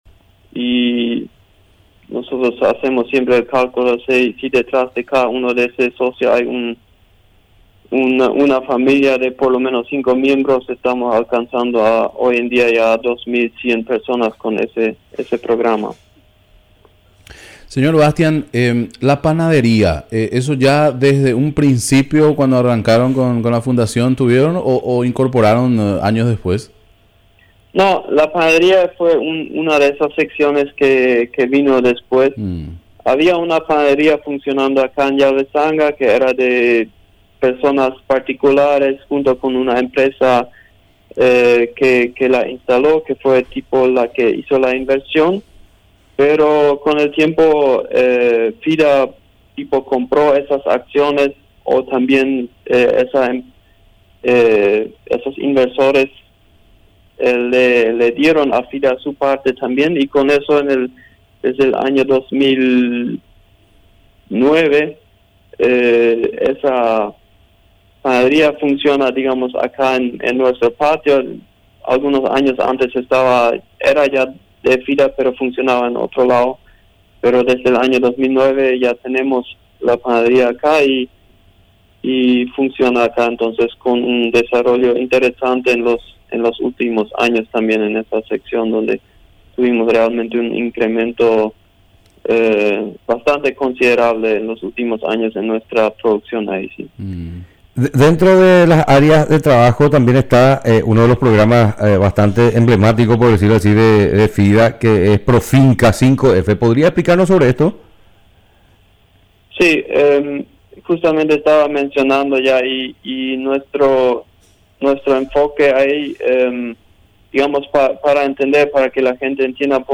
Entrevistas / Matinal 610 Asamblea de la fundación Mar 31 2025 | 00:12:26 1x 00:00 / 00:12:26 Subscribe Share RSS Feed Share Link Embed